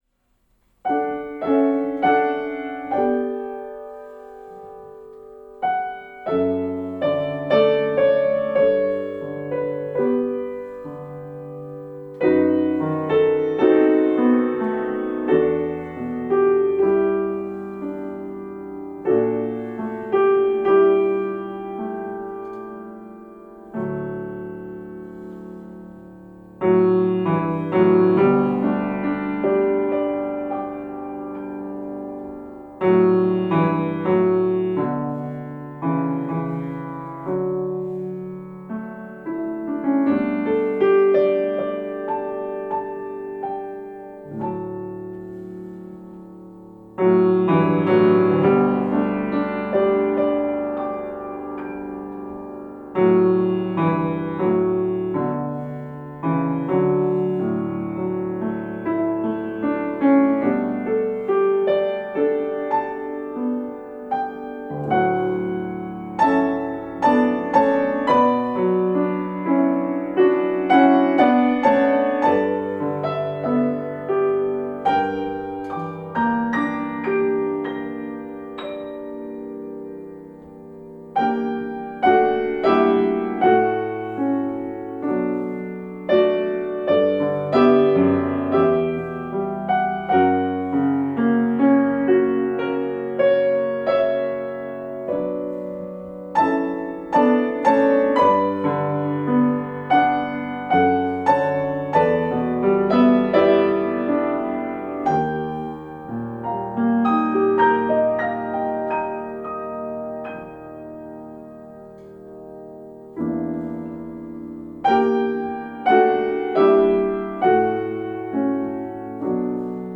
A contemplative piano solo setting of the hymn
Sacred